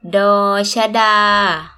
– door / sha – daa
door-scha-daa.mp3